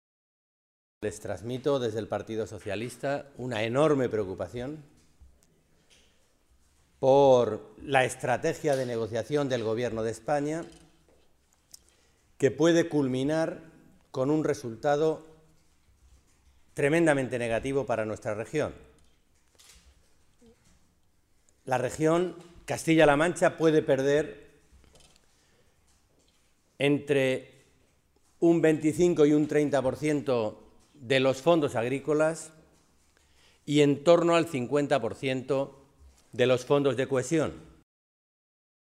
Alejandro Alonso, diputado nacional del PSOE de Castilla-La Mancha
Cortes de audio de la rueda de prensa